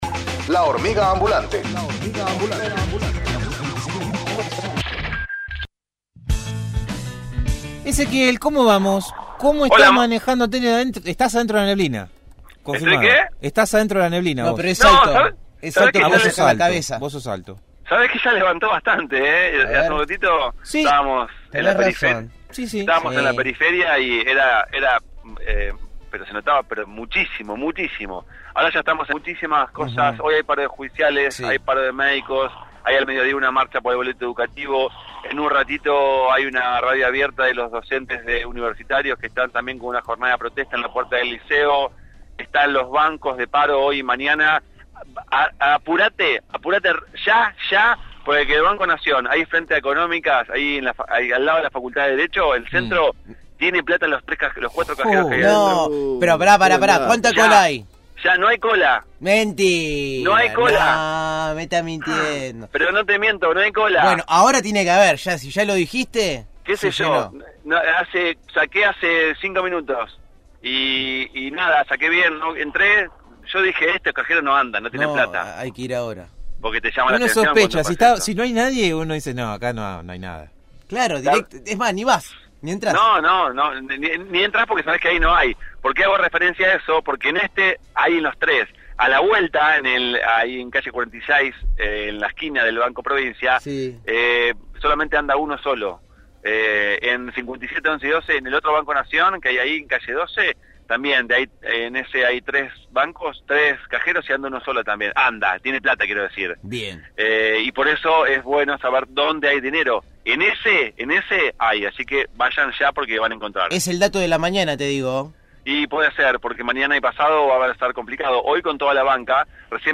MÓVIL/ V Taller de Planificación Estratégica en Extensión